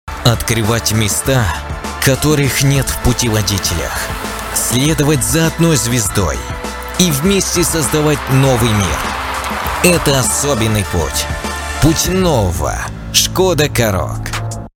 Муж, Автоответчик
Звуковая карта focusrite solo 3rd, микрофон se electronics x1 s